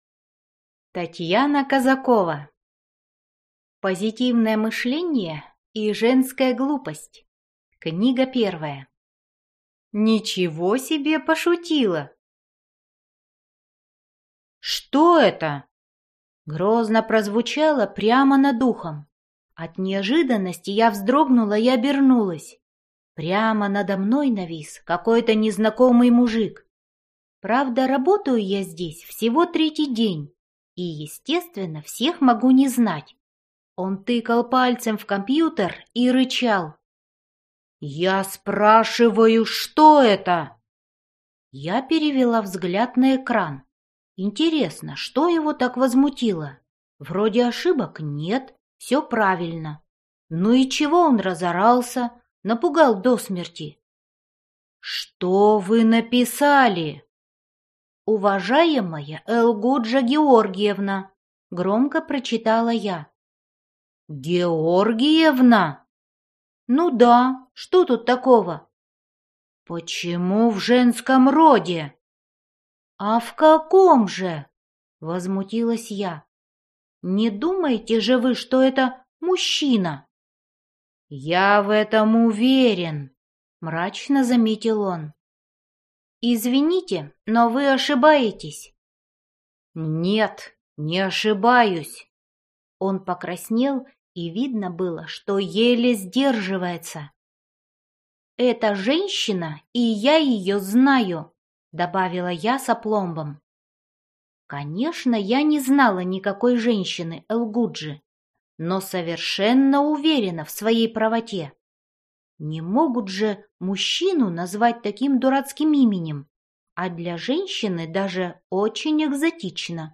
Аудиокнига Ничего себе пошутила - купить, скачать и слушать онлайн | КнигоПоиск